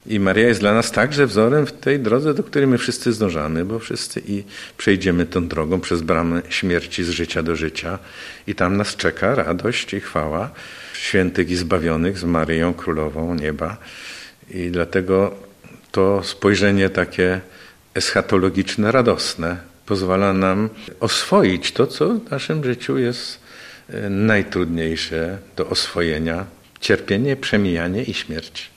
Staramy się naśladować Maryję w jej wierze i miłości – mówi lubelski metropolita abp Stanisław Budzik.